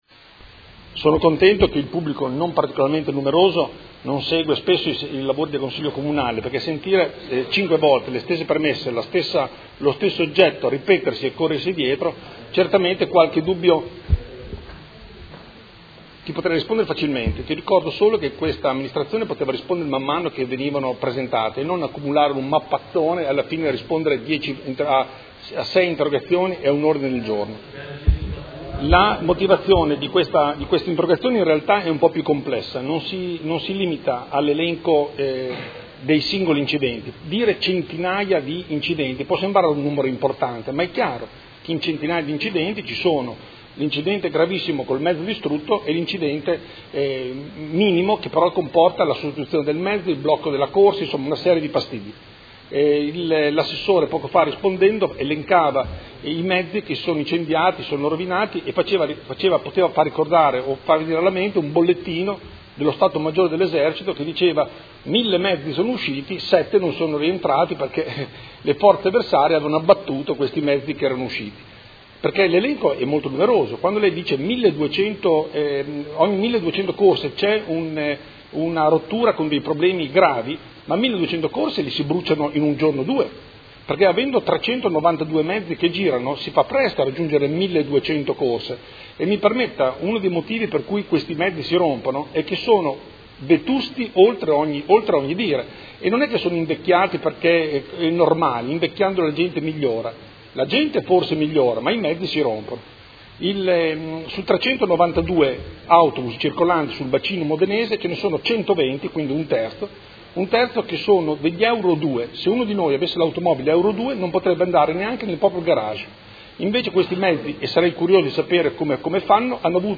Seduta del 21/06/2018 Interrogazione del Consigliere Galli (FI) avente per oggetto: SETA, dopo centinaia di guasti durante il servizio con personale e passeggeri coinvolti e 9 incendi, o principi d’incendio, dei mezzi la misura è colma